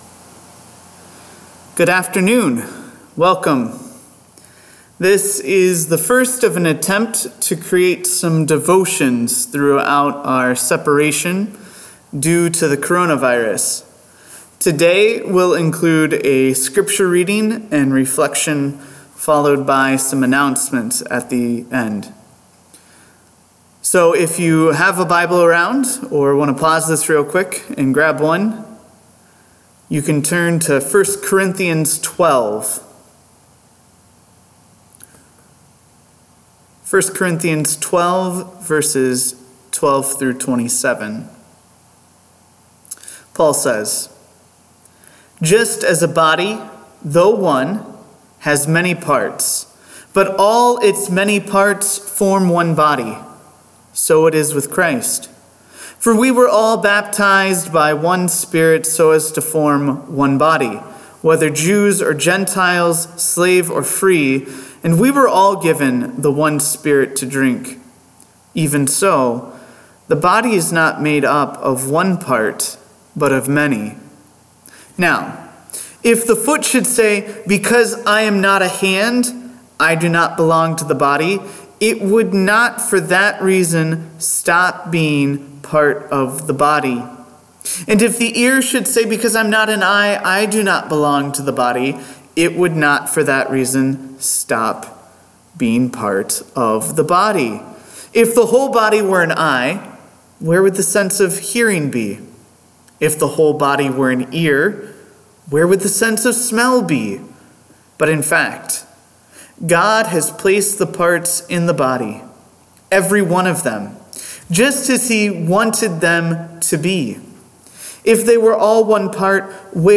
3/17/2020 Devotional and Announcements